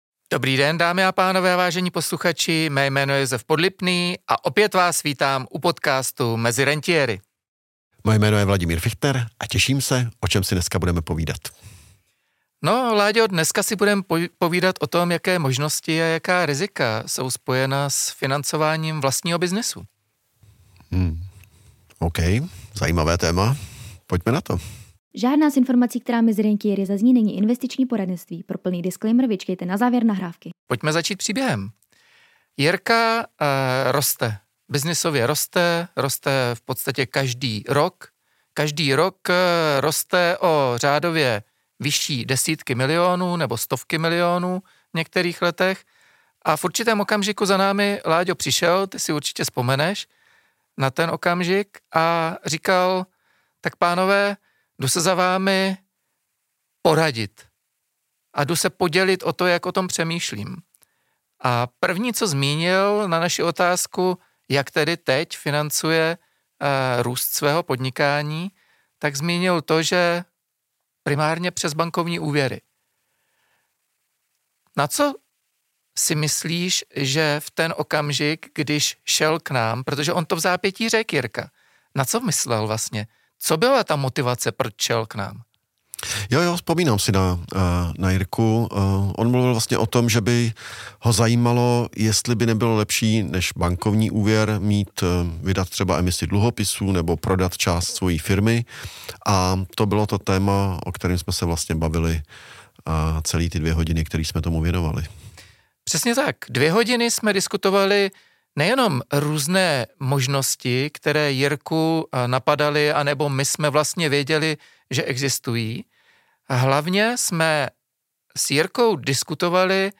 diskuze